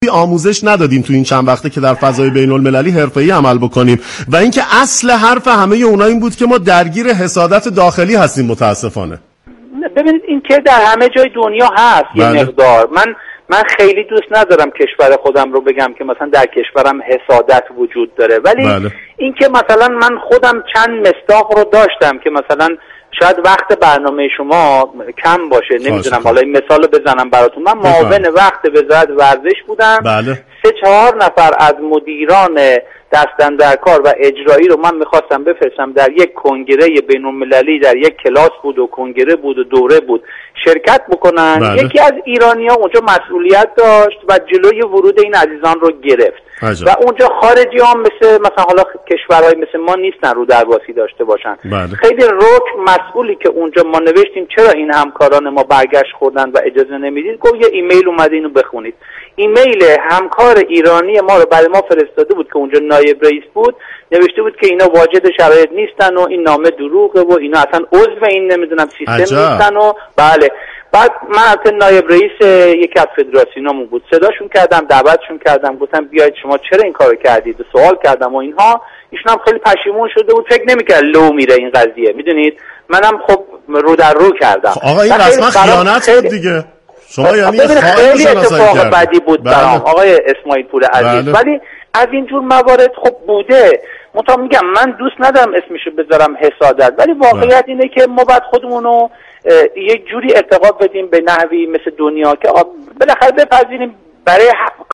از همین رو آفساید رادیوجوان در این زمینه به گفتگو با آقای حمیدسجادی معاون سابق وزارت ورزش و جوانان پرداخته است. آقای سجادی در این گفت و شنود تلفنی با اشاره به هزینه‌های سنگین در ورزش كشورمان برای حضوری قدرتمند در عرصه بازی‌های داخلی و خارجی انجام میشود گفت: متاسفانه در ازای این هزینه‌ها و علیرغم موفقیت‌ها كمتر كرسی بین اللمللی برای احقاق حقوق ورزش ایران كسب می‌شود.